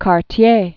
(kär-tyā, kärtē-ā), Sir George Étienne 1814-1873.